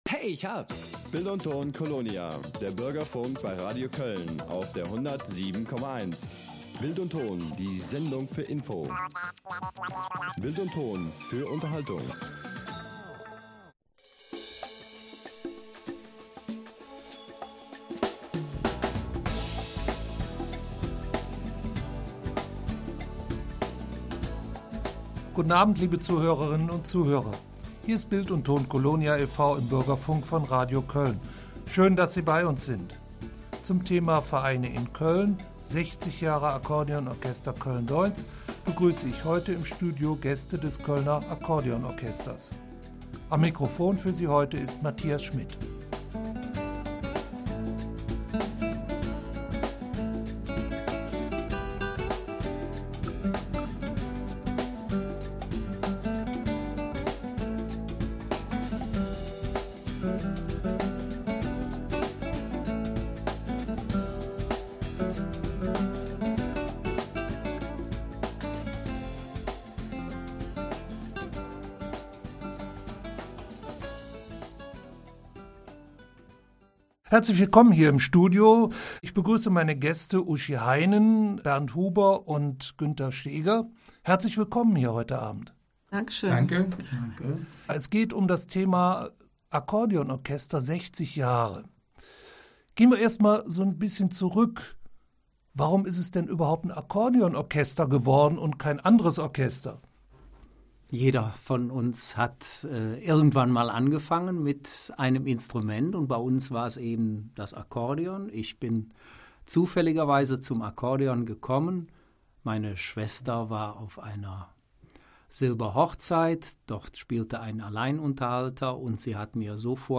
Interview 55 Jahre Akkordeon Orchester Köln Deutz